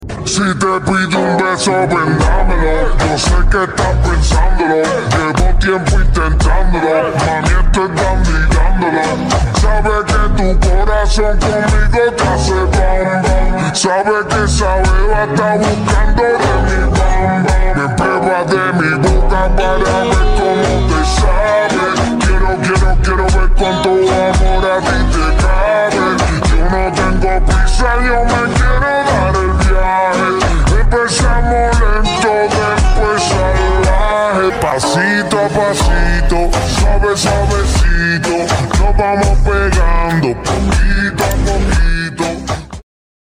Hydra Monster VS All Serpent sound effects free download